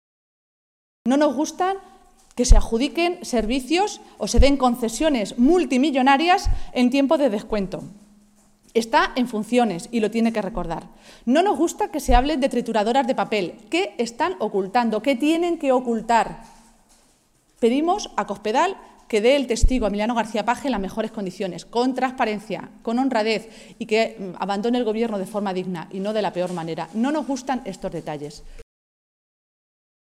Maestre se pronunciaba de esta manera esta mañana, en Toledo, en una comparecencia ante los medios de comunicación en la que, además de asegurar que se va a estudiar la legalidad de esas decisiones, las ha definido como “poco éticas”.
Cortes de audio de la rueda de prensa